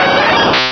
Cri de Pyroli dans Pokémon Rubis et Saphir.